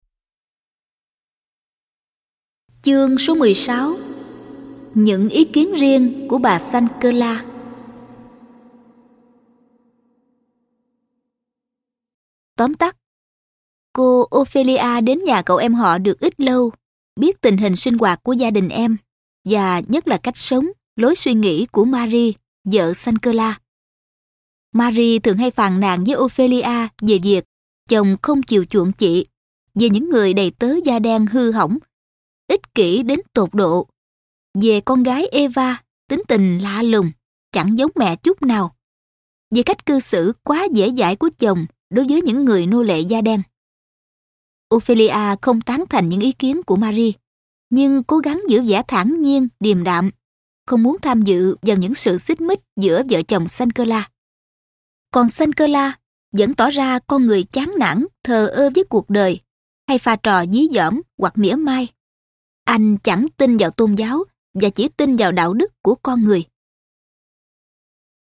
Sách nói | Túp lều bác Tom – Harriet Beecher Stowe - phần 4-> 10